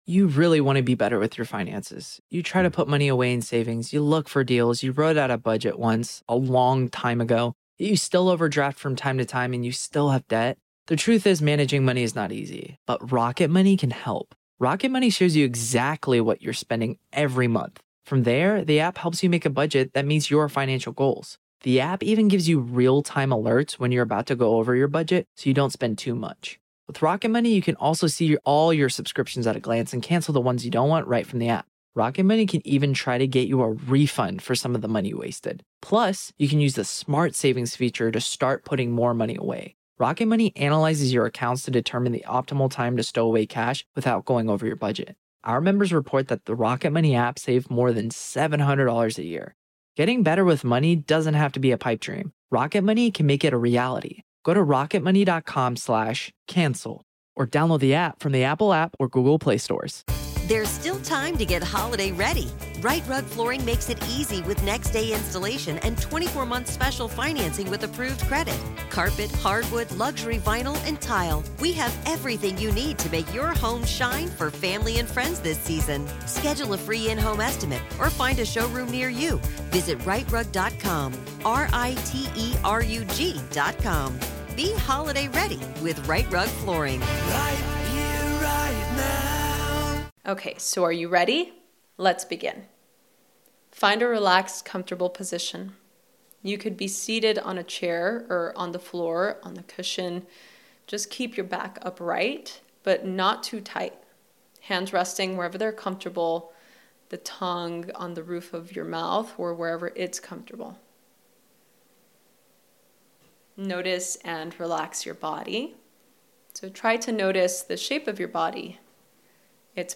Episode 119| MEDITATION| Breathing Mindfully *repost